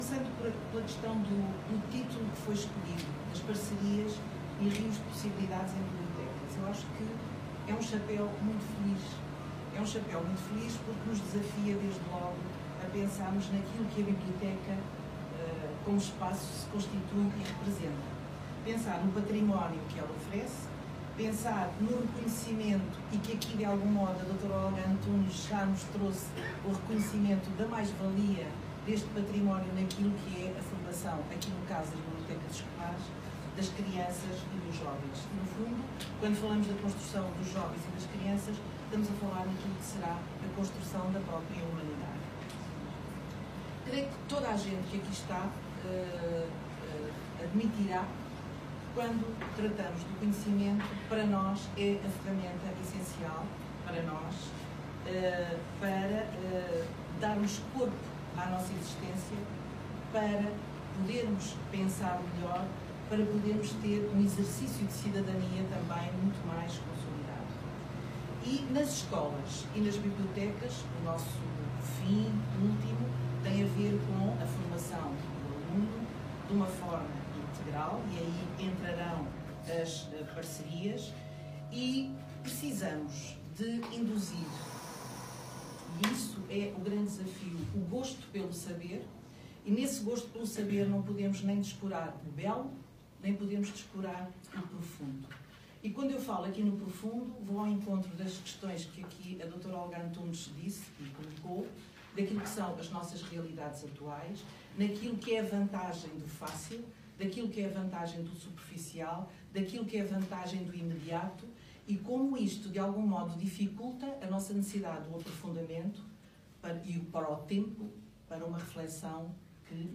III Encontro da Rede de Bibliotecas do Concelho de Constância | sessão de abertura